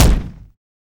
impact_projectile_004.wav